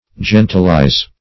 Gentilize \Gen"til*ize\, v. i.